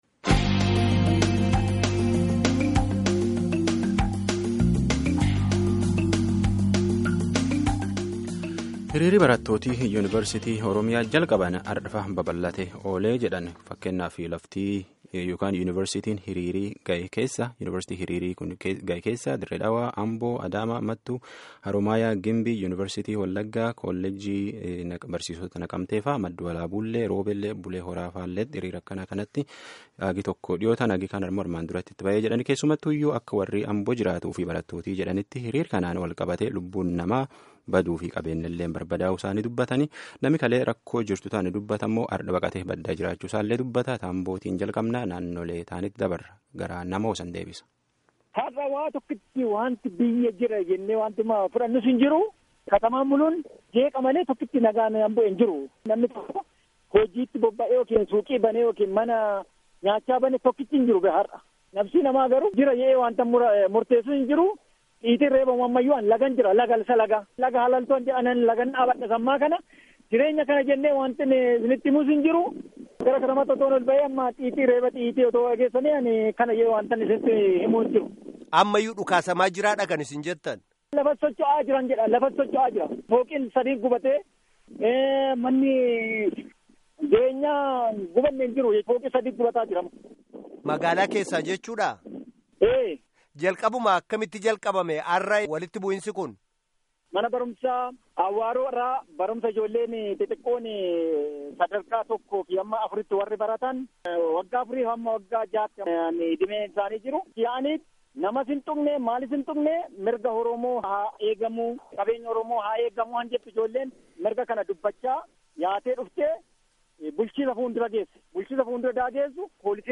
Guutummaa gaaffii fi deebii kanaa dhaggeeffadhaa